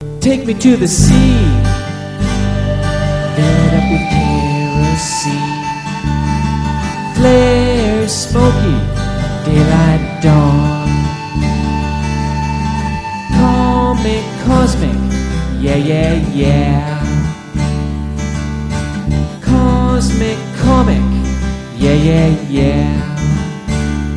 gaseous keyboards
acoustic guitar